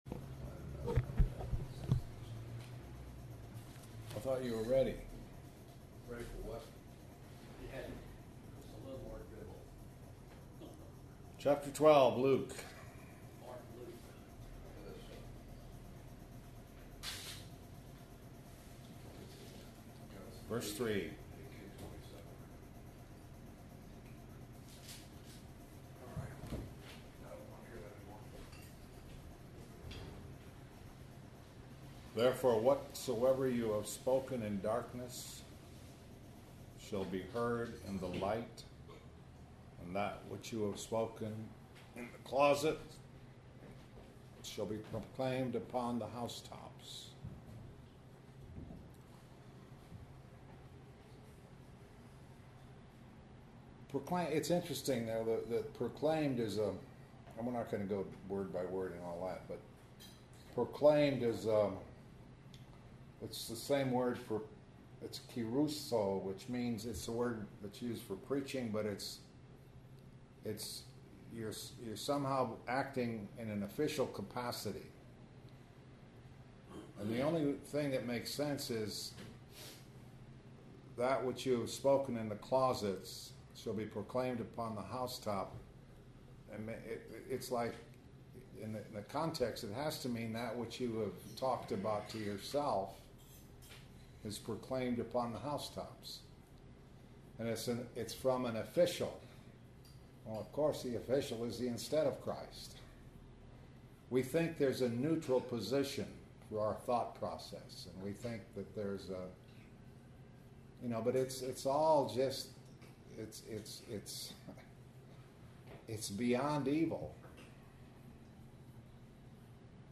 Nov. 5, 2013 – Luke 12:3-5 Posted on December 18, 2013 by admin Nov. 5, 2013 – Luke 12:3-5 Proclaimed II Cor. 10:3-6 John 1:1 This entry was posted in Morning Bible Studies .